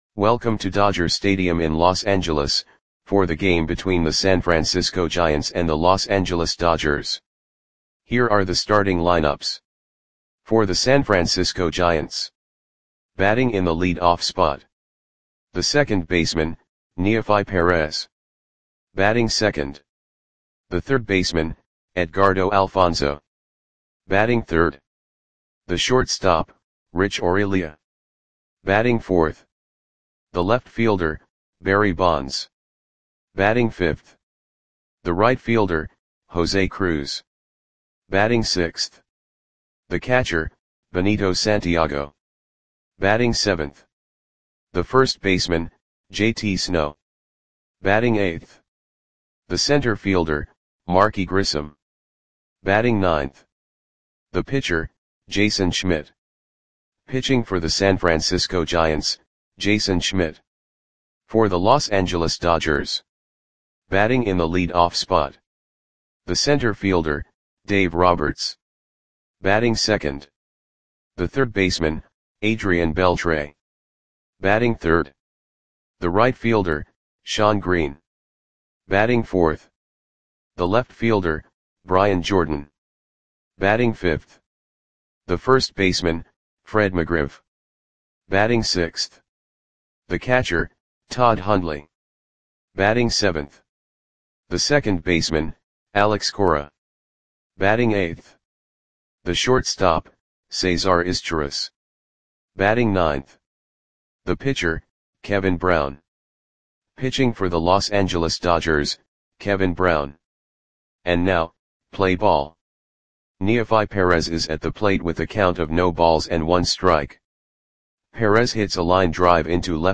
Lineups for the Los Angeles Dodgers versus San Francisco Giants baseball game on April 18, 2003 at Dodger Stadium (Los Angeles, CA).
Click the button below to listen to the audio play-by-play.